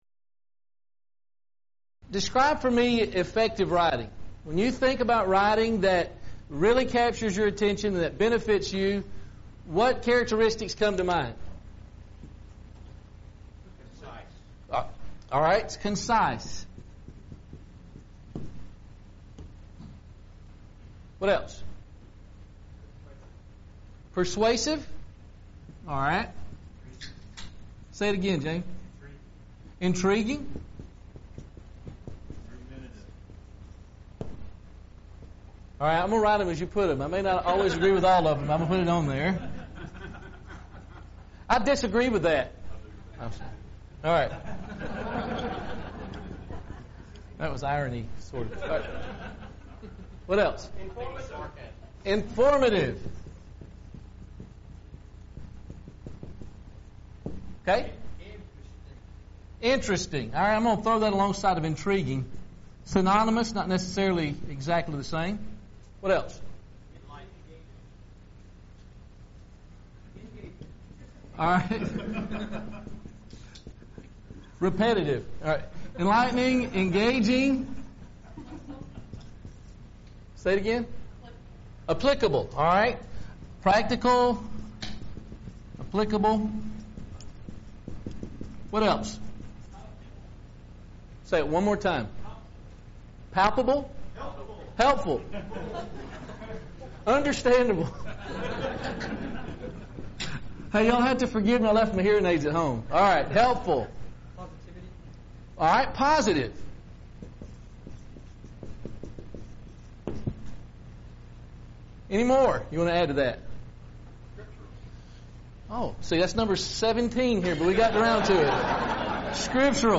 Event: 2018 Focal Point Theme/Title: Preacher's Workshop
lecture